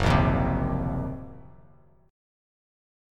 F#6b5 chord